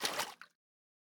PenguinSplash-001.wav